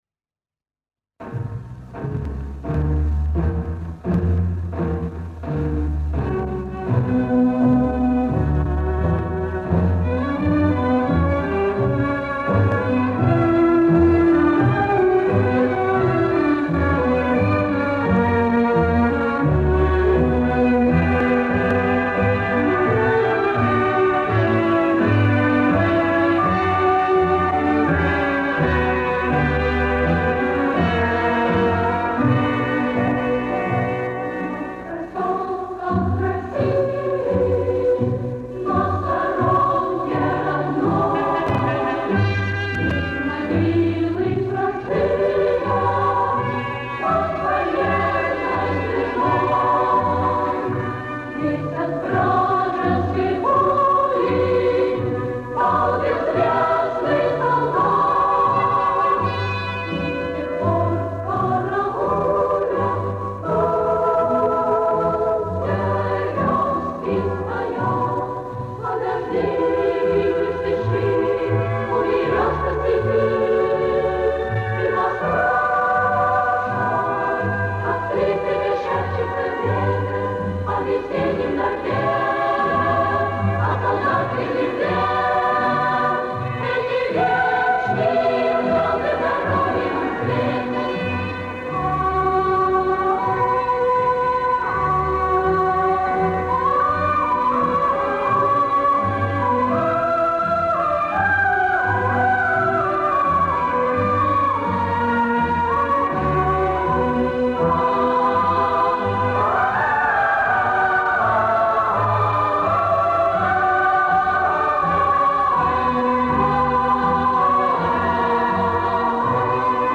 Пионерская патриотическая песня.